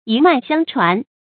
一脈相傳 注音： ㄧ ㄇㄞˋ ㄒㄧㄤ ㄔㄨㄢˊ 讀音讀法： 意思解釋： 從同一血統、派別世代相承流傳下來。